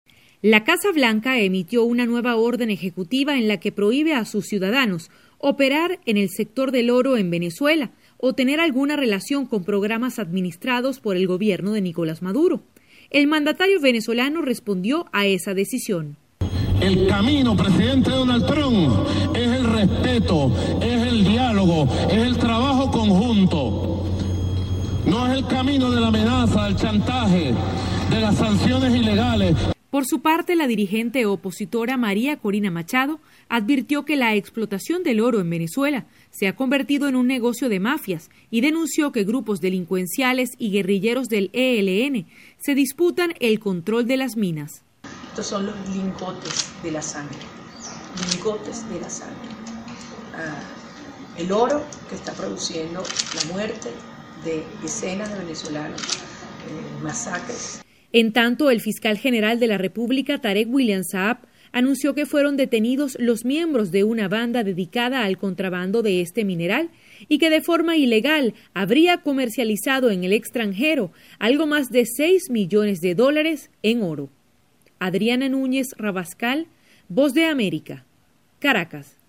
VOA: Informe desde Venezuela